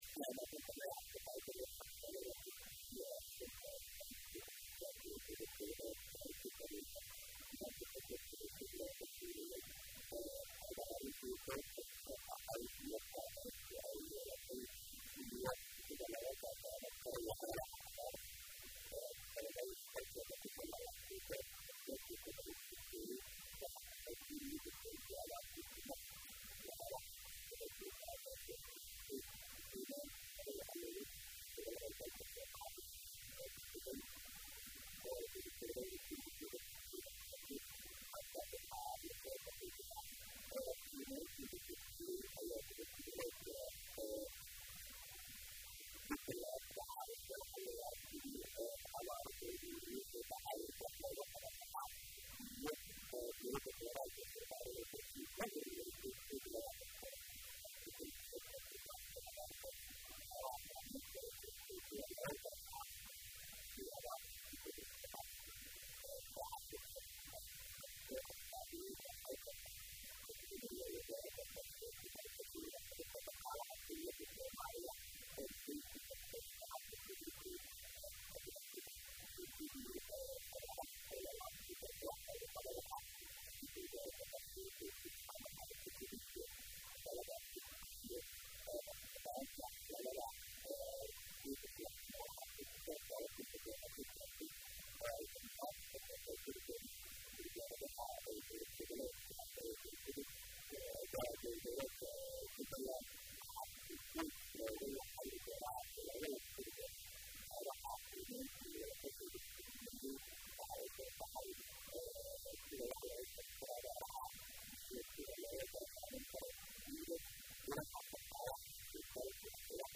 Wareysiyada